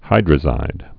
(hīdrə-zīd)